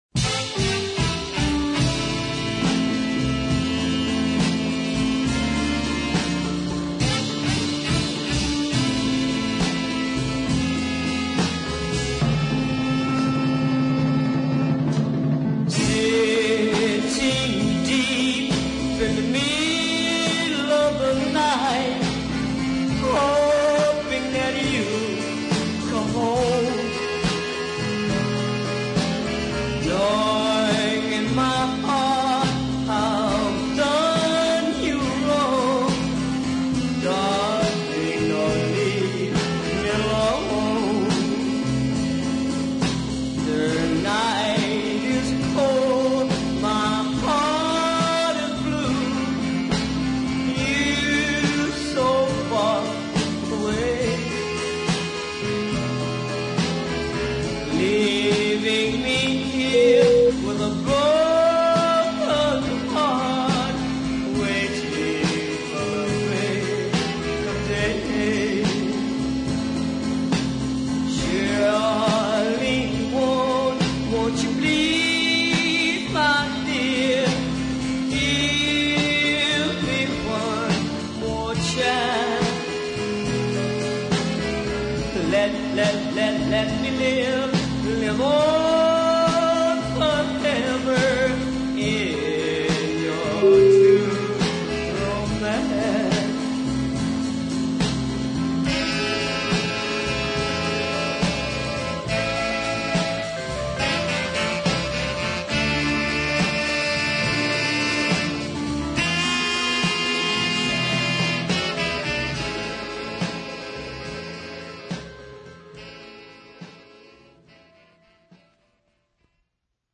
But this single is a smashing example of early soul.